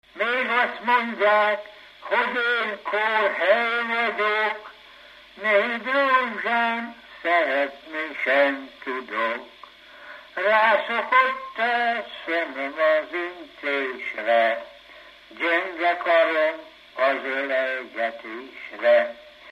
Alföld - Pest-Pilis-Solt-Kiskun vm. - Kiskunhalas
ének
Stílus: 1.2. Ereszkedő pásztordalok
Kadencia: 5 (1) 1 1